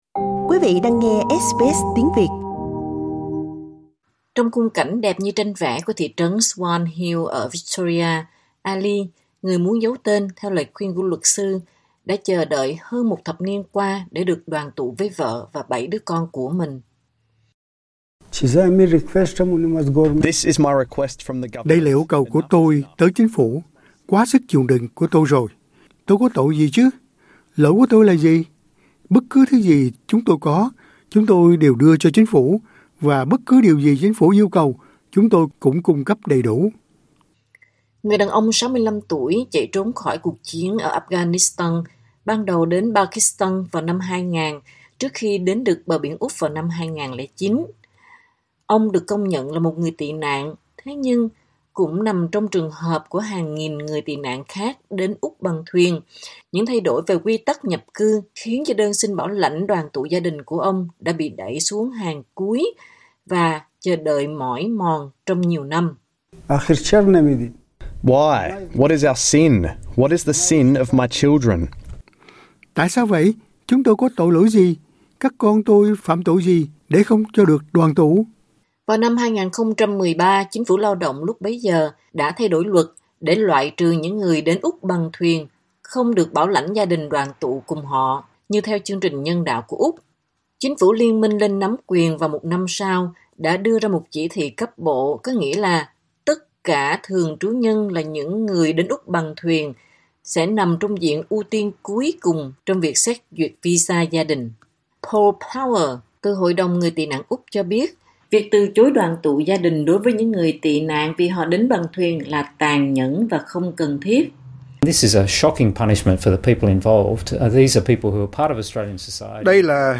Nhiều người đã tới Úc từ năm 2009 nhưng đến nay việc đoàn tụ gia đình dường chưa có hồi kết, một sự trì hoãn vô thời hạn như họ nói. Phóng sự đặc biệt này, SBS News đã đến thăm thành phố nông thôn Swan Hill, phía bắc Melbourne để nghe câu chuyện của họ.